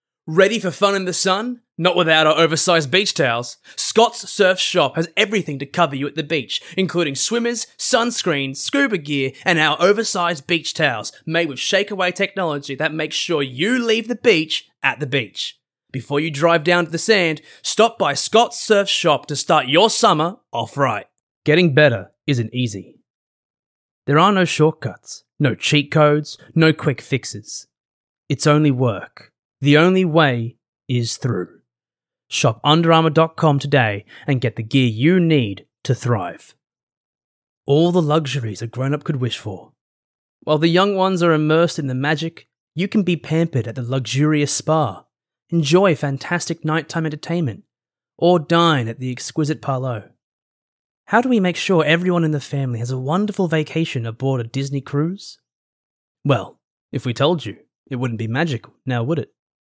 Commercial Voice Over Talent for Radio & TV Ads
Conversational, upbeat and real.
British English & Scottish.